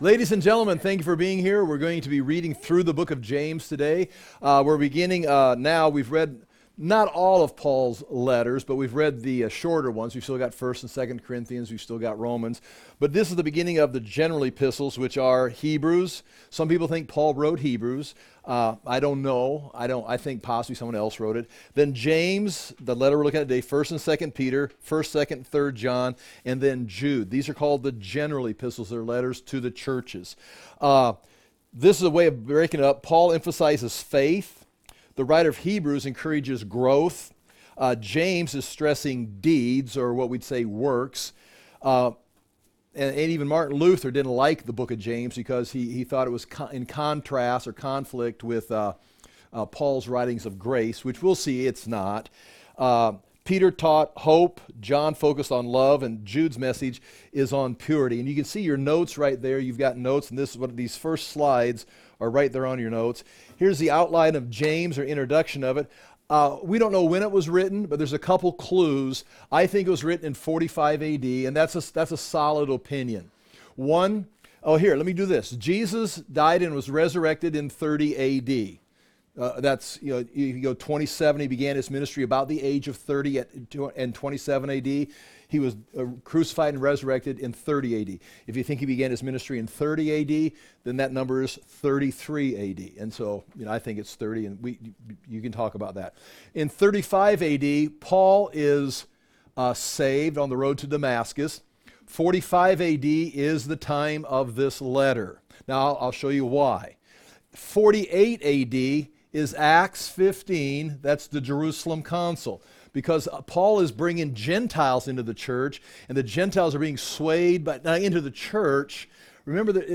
We did this series in six months reading through all of the Epistles aload on livestream between July-December of 2025.